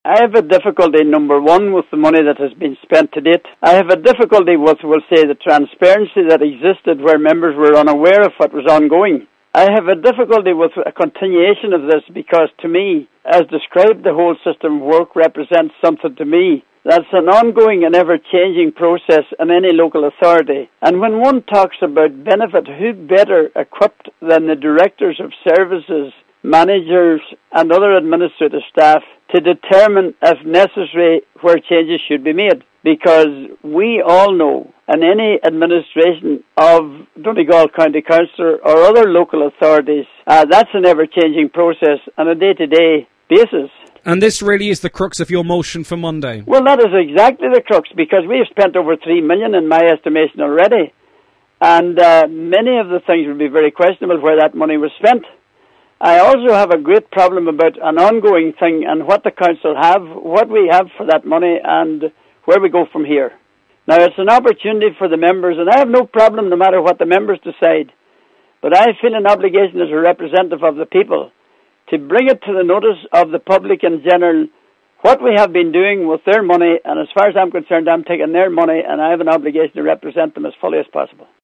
Councillor Ian McGarvey says that he is to raise the issue with the council next week as he has serious concerns about the’ Whole System of Work’: